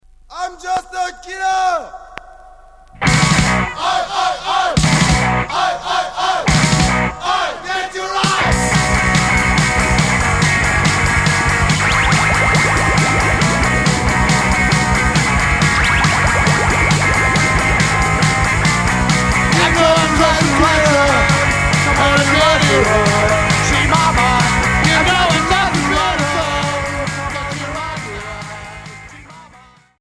Street punk